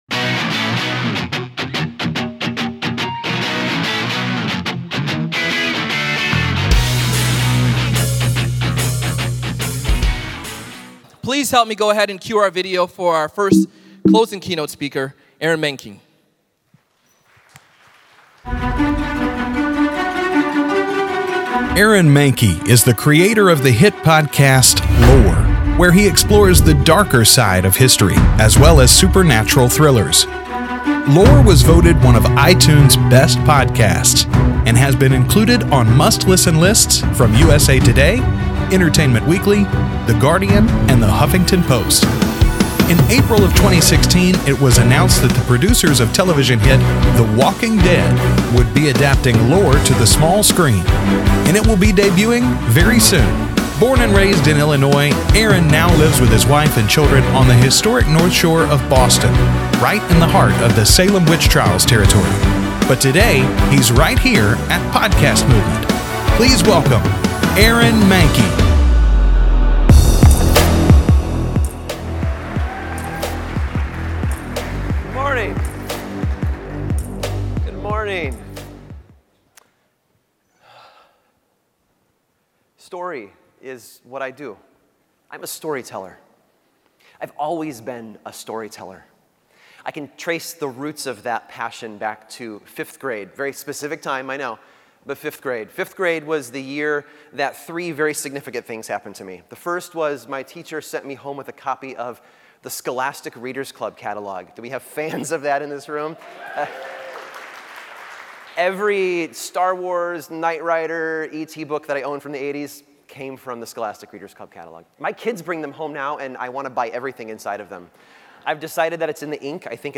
Aaron Mahnke - Closing Keynote